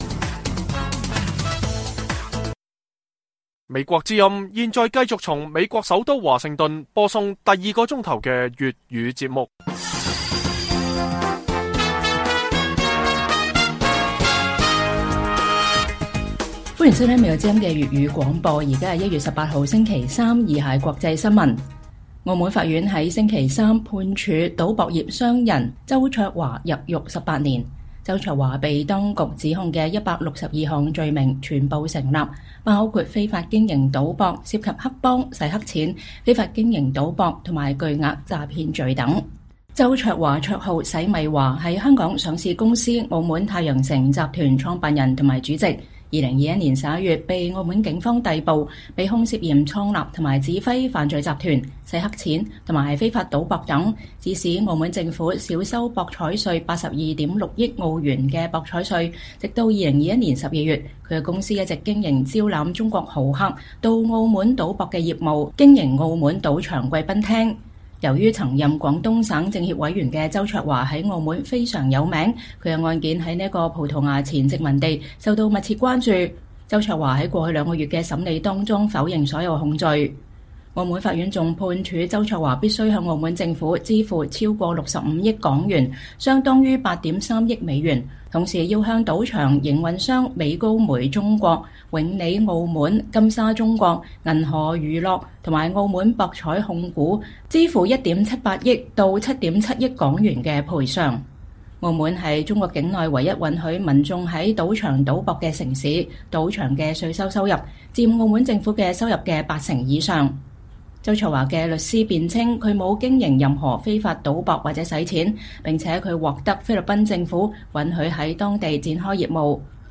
粵語新聞 晚上10-11點：澳門賭博業老大周焯華涉百罪名被判入獄18年